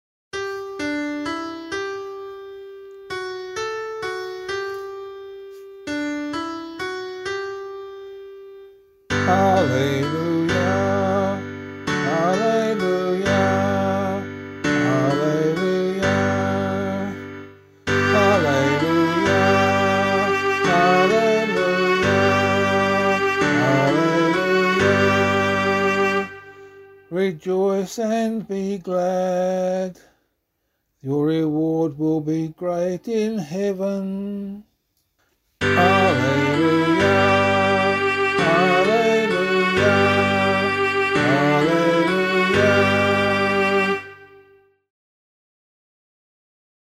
Gospel Acclamation for Australian Catholic liturgy.